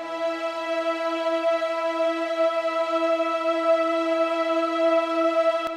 multiplayerpiano / sounds / Orchestra / e6.wav
e6.wav